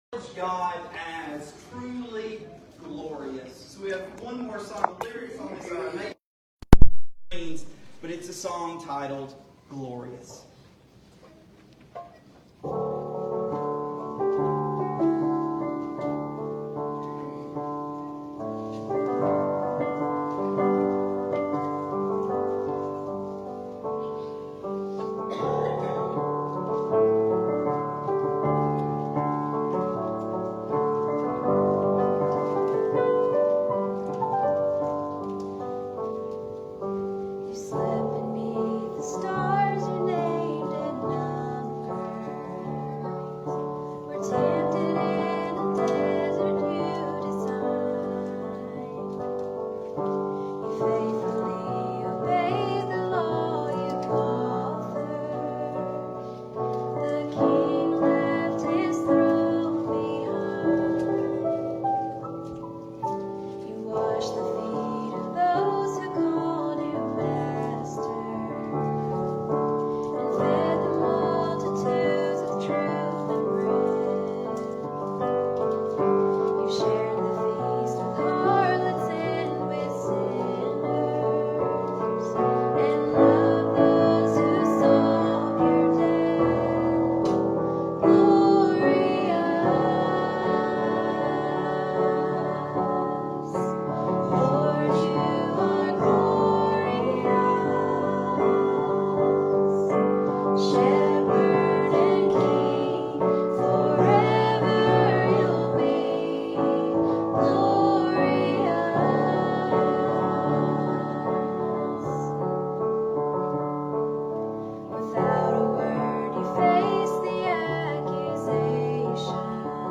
The Biggest Lie Ever Told | SermonAudio Broadcaster is Live View the Live Stream Share this sermon Disabled by adblocker Copy URL Copied!